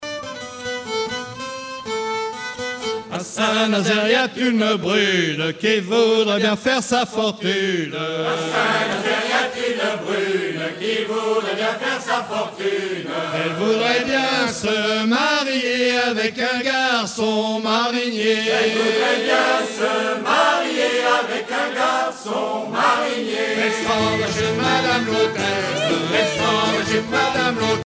Genre dialogue
Pièce musicale éditée